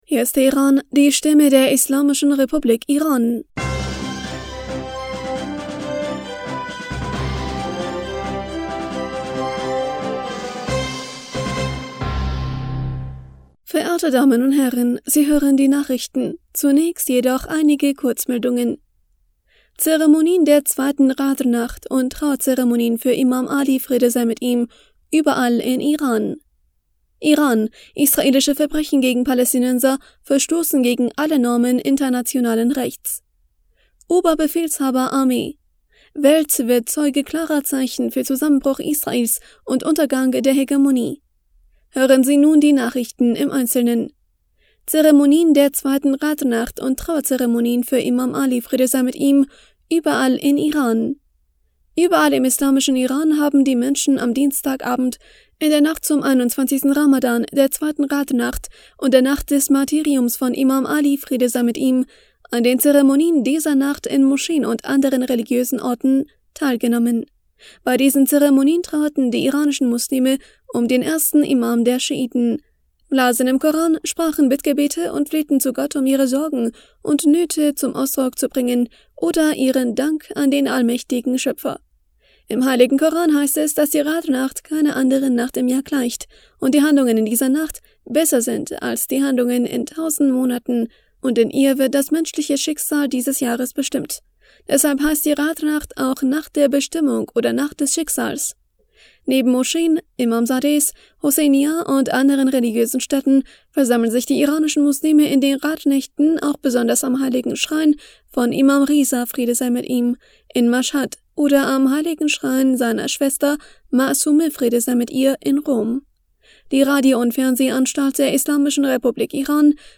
Nachrichten vom 12. April 2023